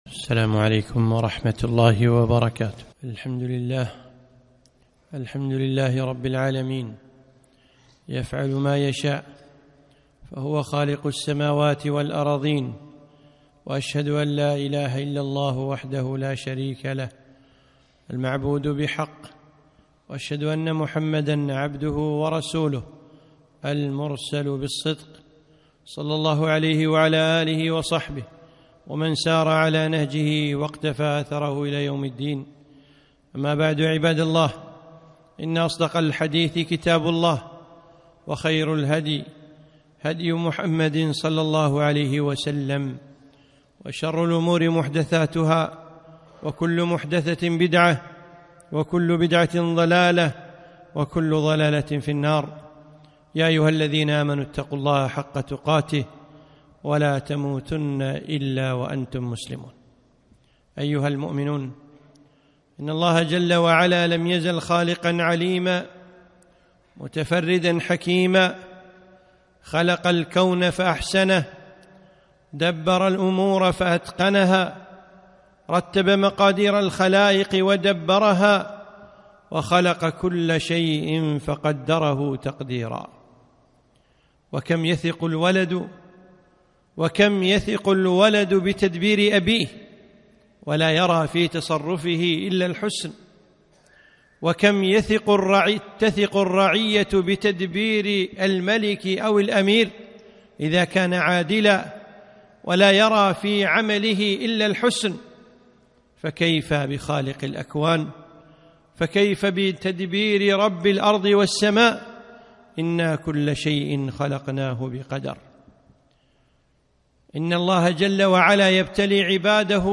خطبة - الصبر على أقدار الله